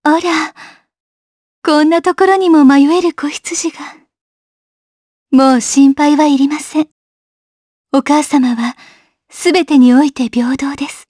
Isaiah-Vox_Get_jp.wav